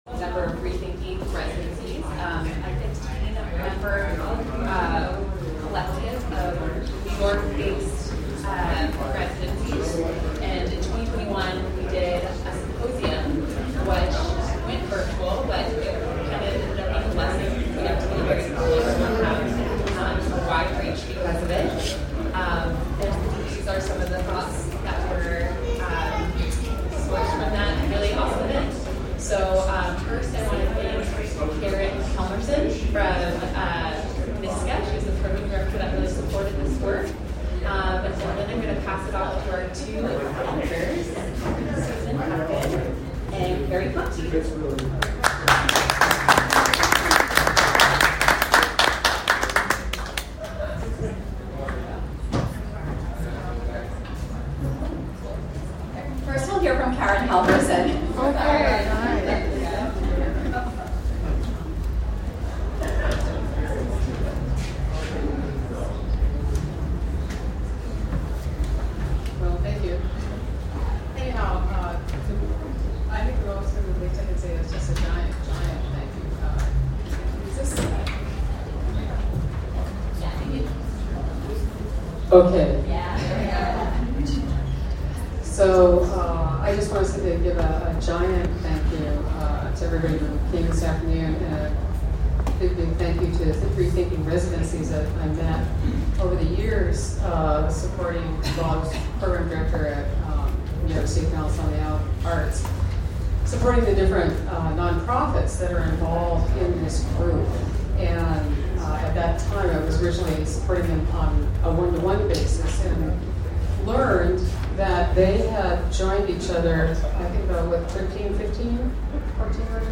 Rethinking Residencies celebrated the publication of Bringing Worlds Together: A Rethinking Residencies Reader on Sunday August 13, 2023 at Pioneer Works in Brooklyn, NY.
Live streamed from Pioneer Works (Brooklyn, NY)